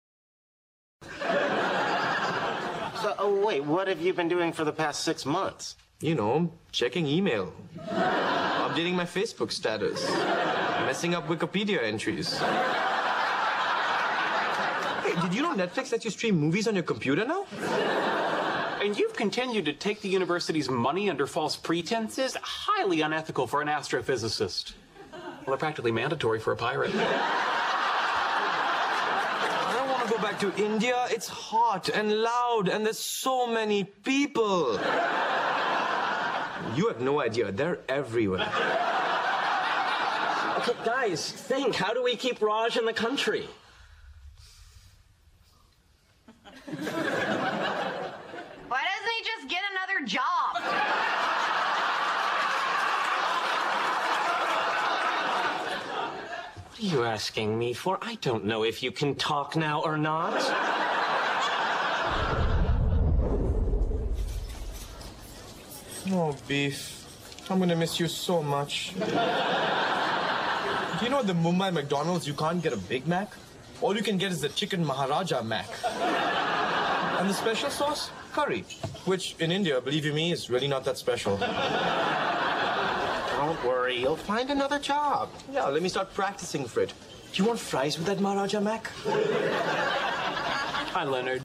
在线英语听力室影视剧中的职场美语 第1期:求职中的听力文件下载,《影视中的职场美语》收录了工作沟通，办公室生活，商务贸易等方面的情景对话。每期除了精彩的影视剧对白，还附有主题句型。